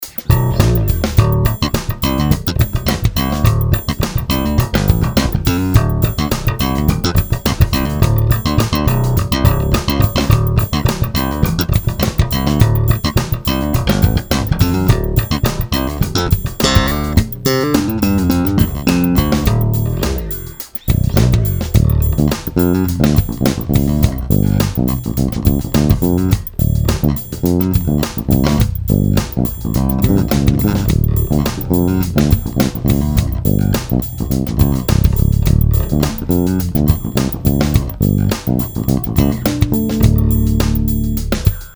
ted ty ukazky :)) EQ je zatim narovno,lehka komprese v kompu. samotny MM a J snimac mozna nahraju pozdeji,ale neni to zadny zazrak :D v ukazkach je blend presne na stredu
MM rozdeleny na singl - ten bliz ke kobylce + J - slap, pak prsty